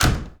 snd_locked_door.wav